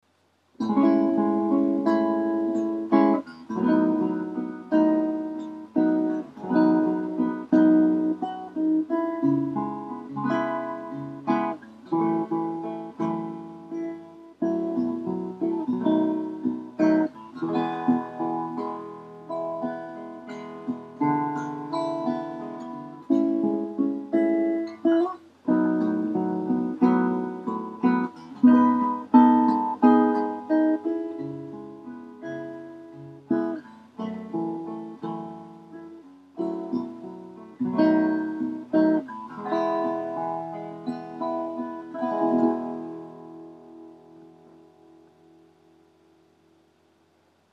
この音源は加工等一切しておりませんので、多少お聞き苦しい点もあるかと思いますが、何度か繰り返し聞いていただきたいと思います。
ギターをつないだアンプから聴こえて来る音をそのまま録音していますので、演奏を聴く人の立場で自分の求めるサウンドイメージを捉えることができると思いますので、是非参考にしてみてください。
■ アコースティックギター（ピックアップマイク単体）
1. ピエゾマイクのみのサウンド
弦の音が特に強調されるので、弦の芯音を捉えたい場合にはおすすめです。